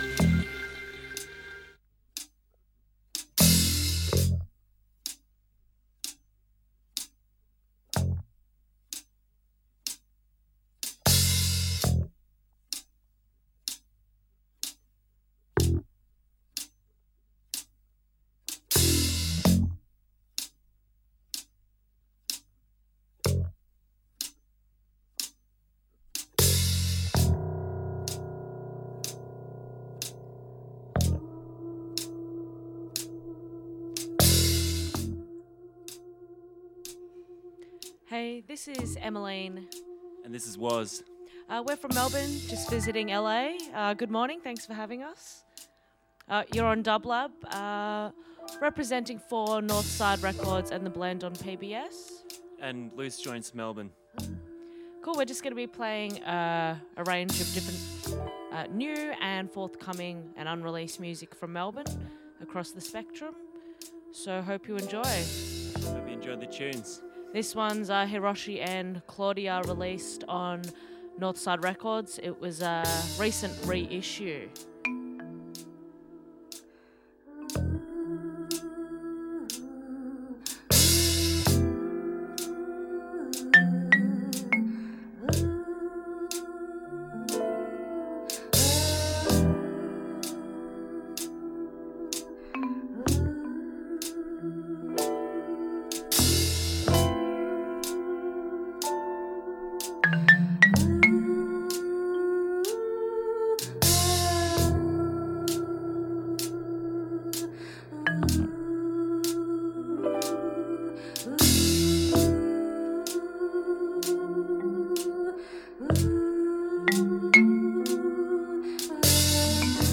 Funk/Soul Hip Hop